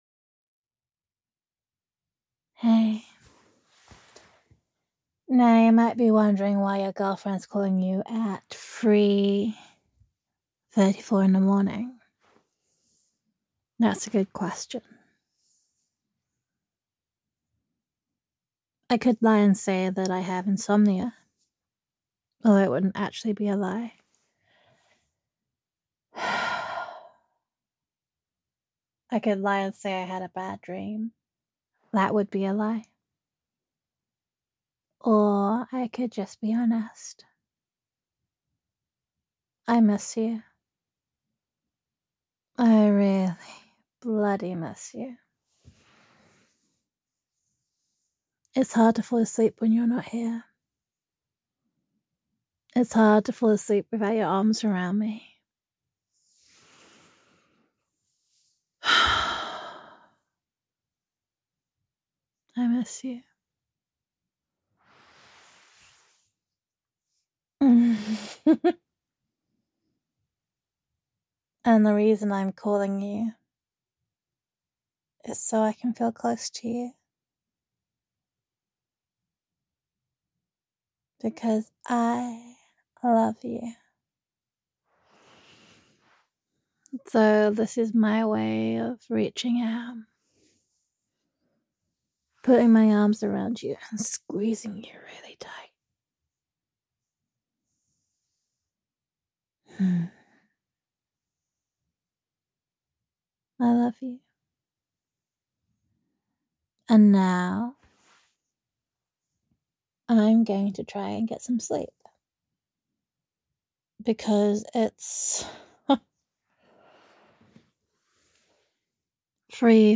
[F4A] Loved [Girlfriend Voicemail][Insomnia][I Miss You][Girlfriend Roleplay][Longing][Gender Neutral][Your Girlfriend Reminds You That You Are Loved]